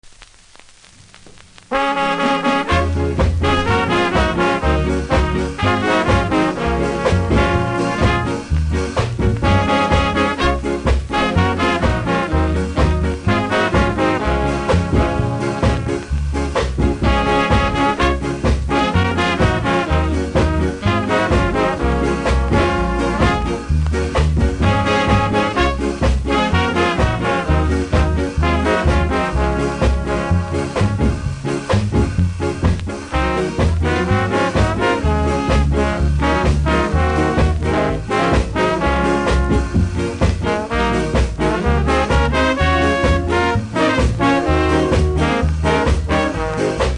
両面プレスノイズあり。
両面キズ多数で見た目悪いですがノイズは見た目ほど酷くありませんので多少のノイズ大丈夫な人ならプレイ可レベル。